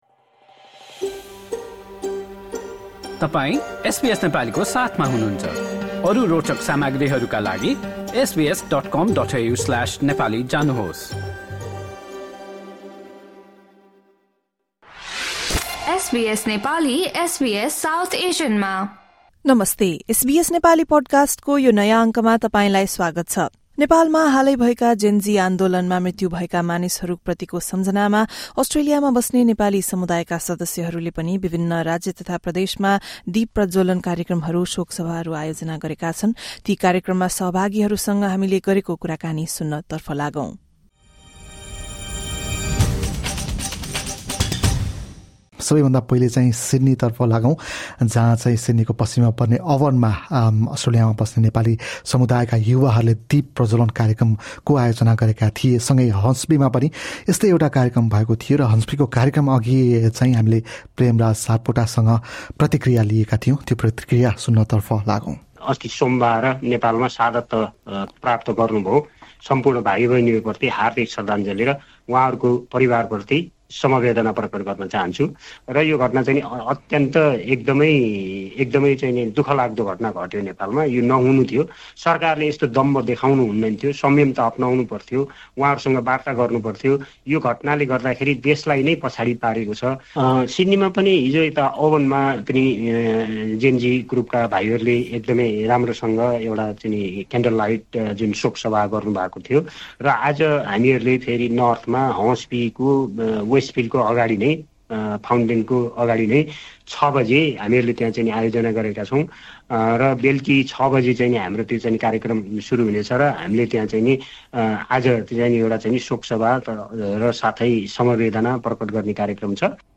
अस्ट्रेलियाका विभिन्न राज्य र प्रदेशमा बस्ने नेपाली समुदायका सदस्यहरूले नेपालमा भएका जेन जी आन्दोलनमा मृत्यु भएका मानिसहरूको सम्झनामा शोक सभा तथा दीप प्रज्वलन आयोजना गरेका छन्। तिनै कार्यक्रमका आयोजक र सहभागीहरूसँग एसबीएस नेपालीले गरेको कुराकानी सुन्नुहोस्।